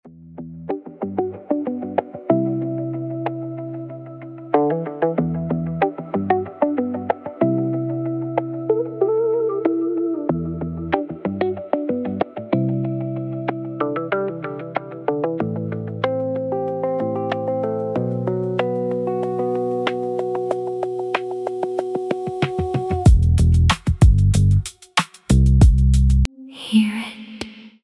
Download free background music for your social media here.